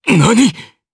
DarkKasel-Vox_Damage_jp_03.wav